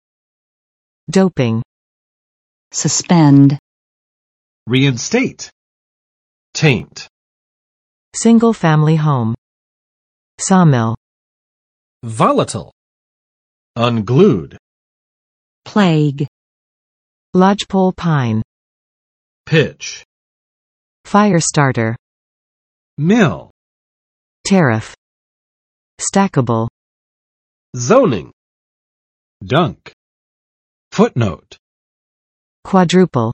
[ˈdopɪŋ] n. 提供兴奋剂；使服用兴奋剂